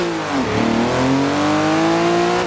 Index of /server/sound/vehicles/sgmcars/997